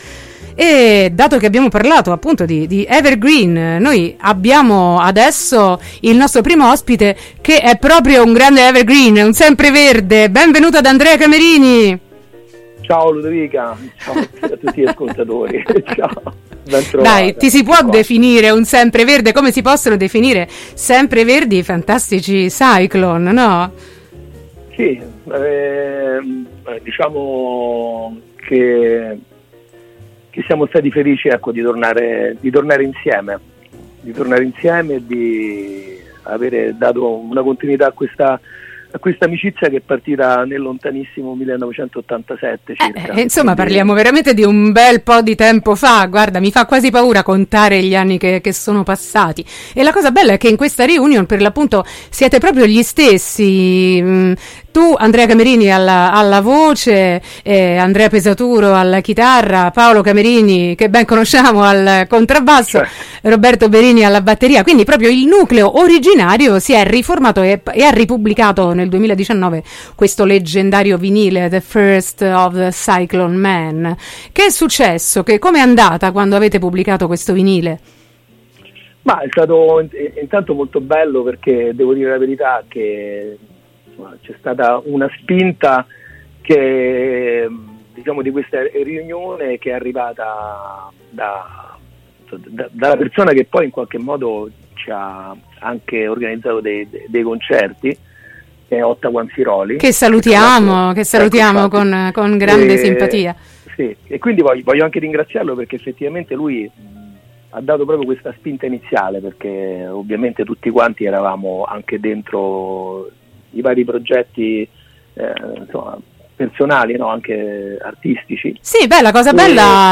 Psychobilly never dies: intervista